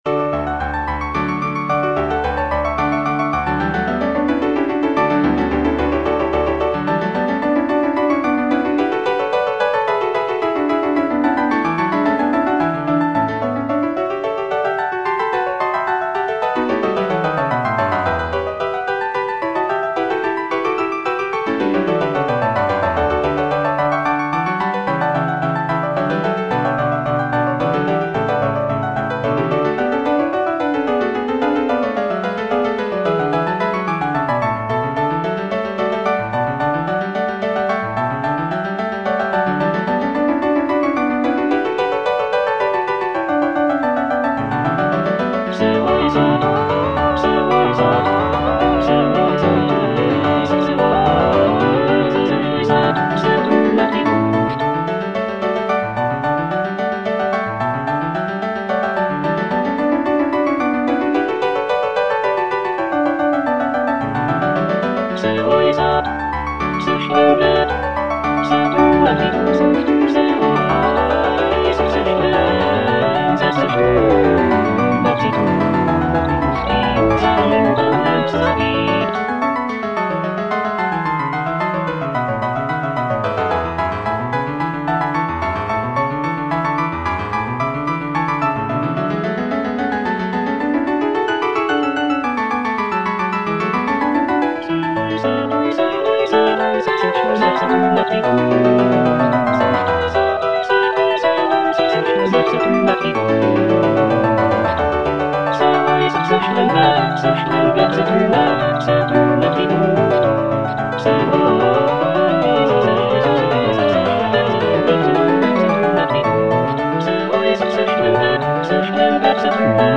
Cantata
All voices